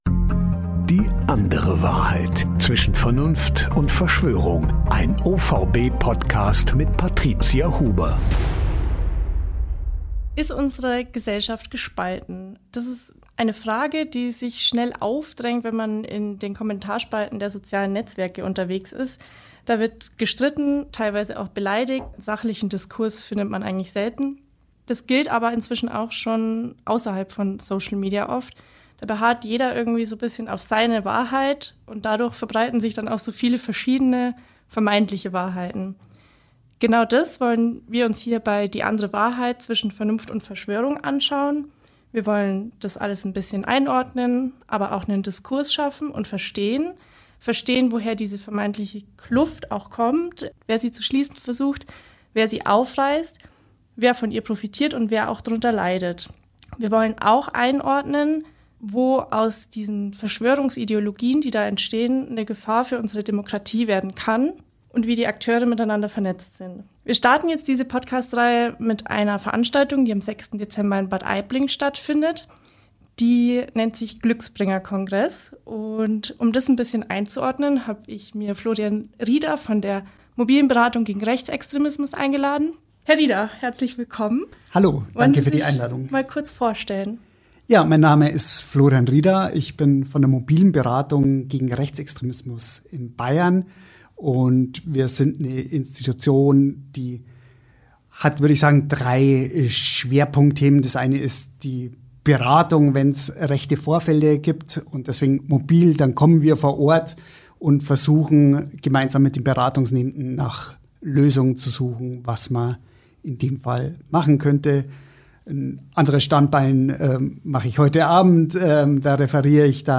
Und auch der Veranstalter des Kongresses wird sich im Podcast äußern. Die Folge wurde teils vor Stattfinden der Veranstaltung aufgezeichnet.